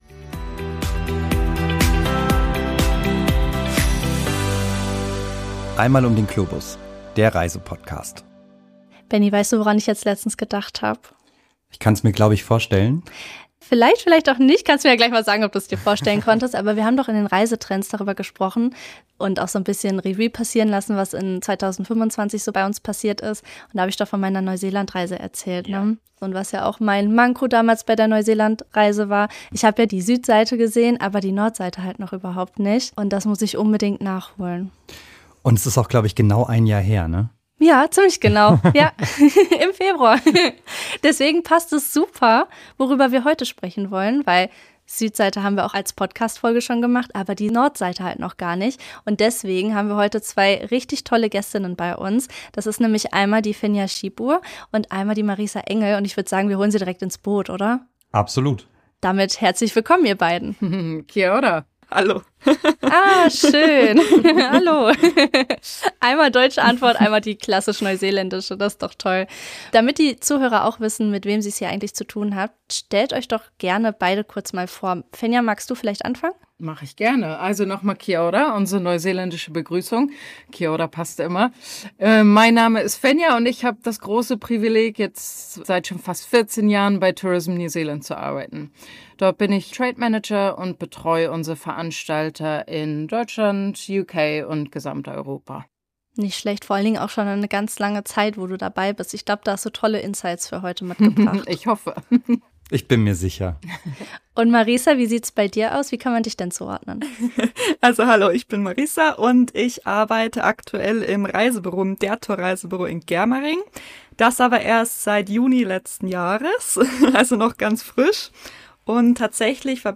Wir sprechen mit zwei echten Neuseeland-Expertinnen, die nicht nur touristische Hotspots, sondern auch persönliche Geheimtipps teilen – von subtropischen Stränden bis zu Maori-Kultur und Action-Abenteuern. Erfahre, wie sich Nord- und Südinsel unterscheiden, warum sich ein Abstecher abseits der Klassiker lohnt, welche konkreten Geheimtipps es gibt und was das echte Neuseeland-Feeling ausmacht.